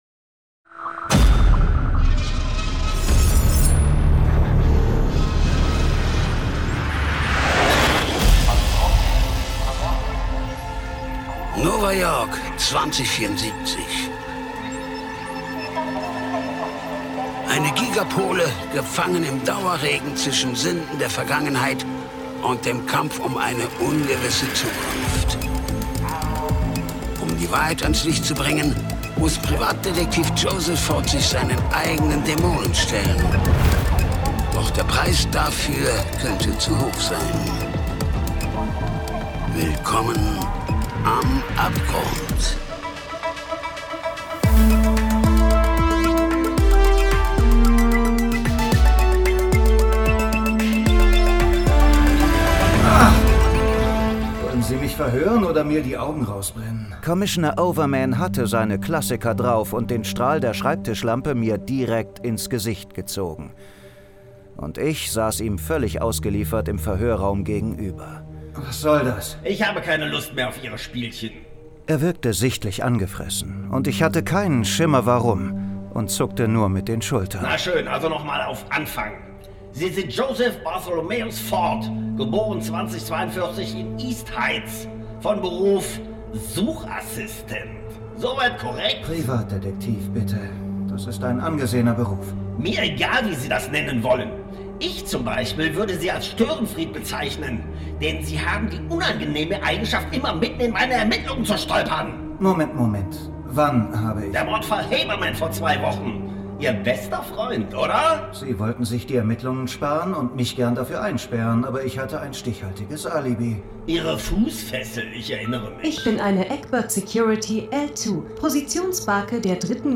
Die SciFi-Noir-Krimi-Hörspielserie Podcast